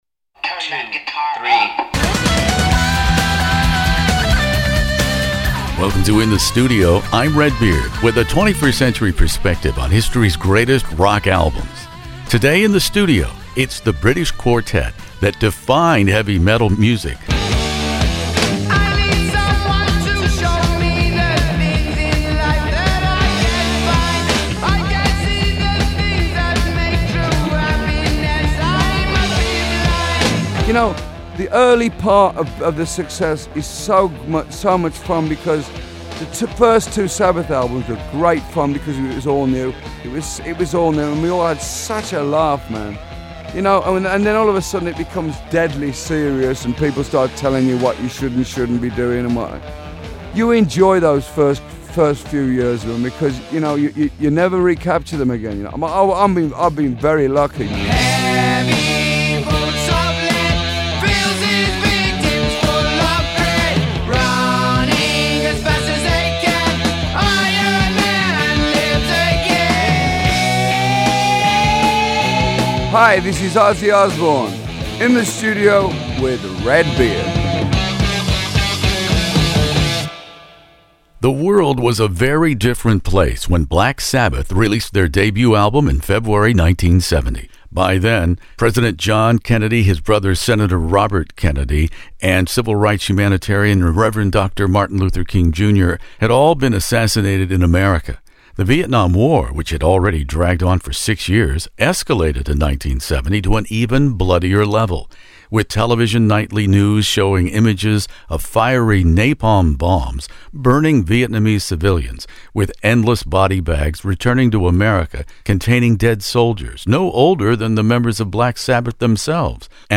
Black Sabbath "Paranoid" interview with Ozzy Osbourne